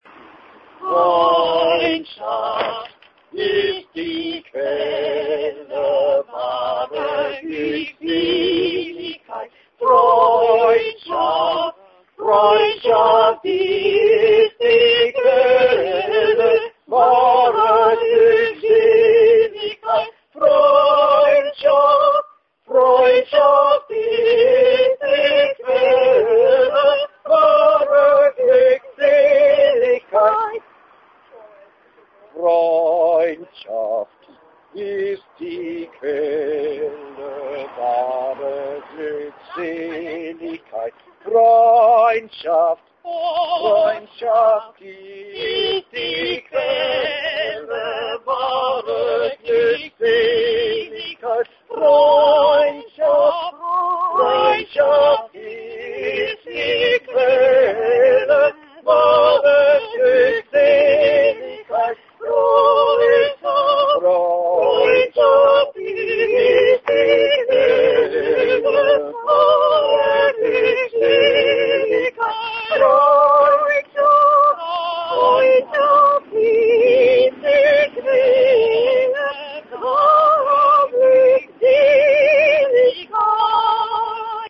Den 18. juni 2007 afholdte Schiller Instituttet en demonstration til støtte for en Femern Bælt-bro foran den tyske ambassade i Stockholmsgade, København. Demonstrationen krydredes af festlig sang (som kan høres ved at klikke på titlerne), bl.a. en kanon af Haydn med en let omskrevet tekst: "Es sagen Ja die Dänen, die Deutschen sagen nein, Ja, Nein, Lass uns die Brücke bauen, denkt nicht so klein, Ein grosses Land denkt nicht so klein" (Danskerne siger ja, tyskerne siger nej, ja, nej, lad os bygge broen, tænk ikke så småt, et stort land tænker ikke småt), Freude Schöne Götterfunken, Die Gedanken Sind Frei,